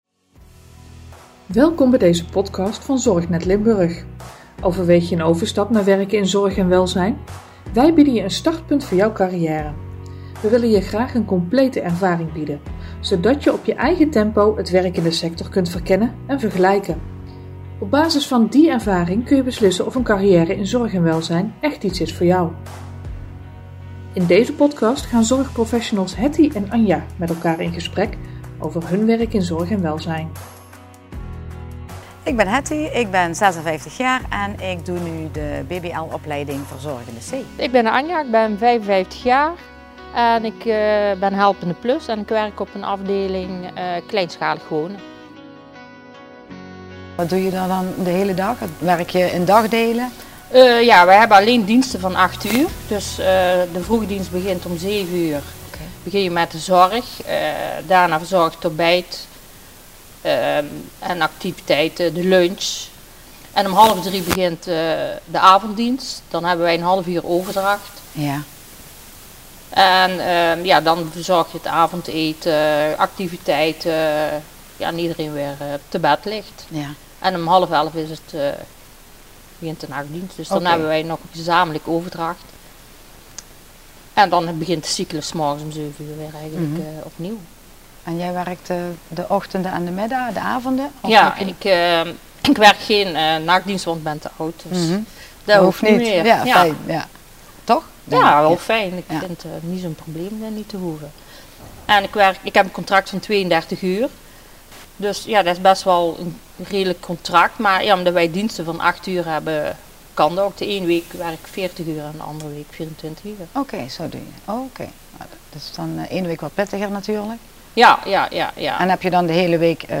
In een reeks video's gaan tien zorgprofessionals gingen met elkaar in gesprek over de overeenkomsten én verschillen in hun beroep. De grote overeenkomst? Hun passie voor de zorg!